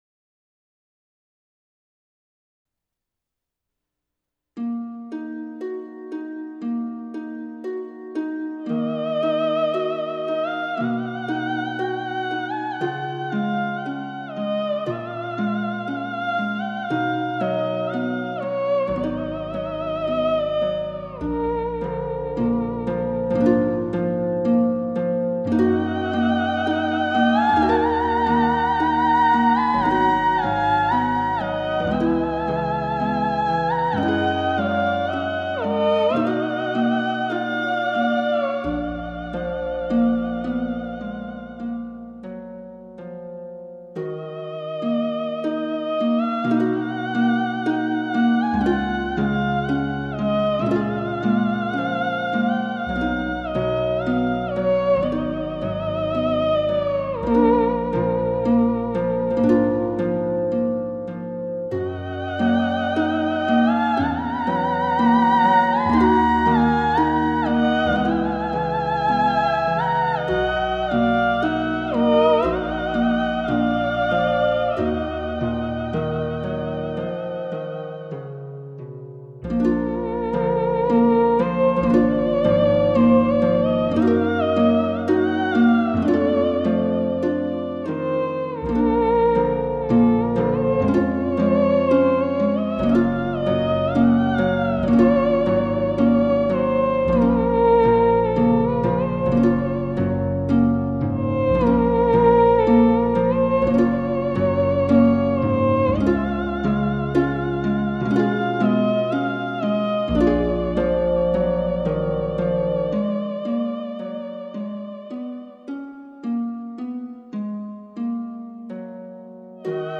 05 duo harpe theremine.mp3